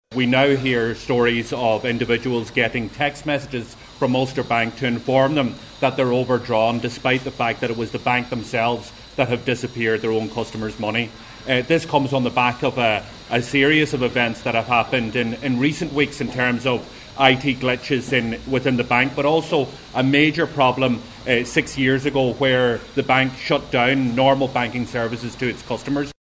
Donegal Deputy Pearse Doherty says it’s not the first time Ulster Bank customers have had issues: